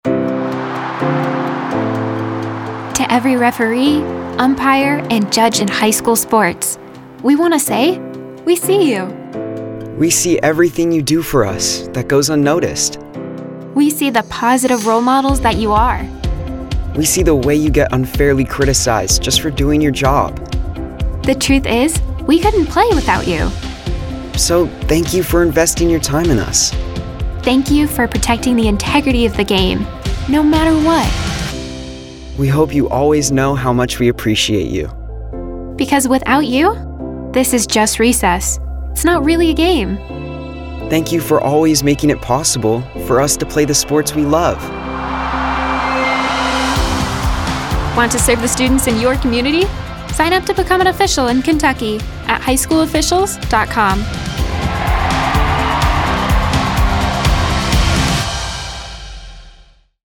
24-25 Radio – Public Service Announcements